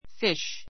fish 小 A1 fíʃ ふィ シュ 名詞 複 fish fishes fíʃiz ふィ シェ ズ ❶ 魚 ✓ POINT 単数も複数も同じ形.